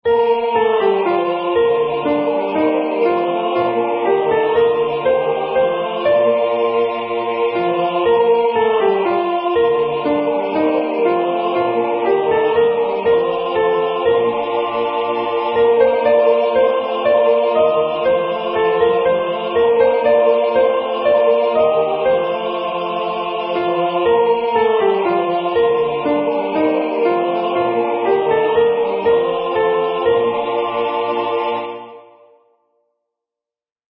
Practice Files: Soprano:
Number of voices: 4vv   Voicing: SATB
Genre: SacredHymn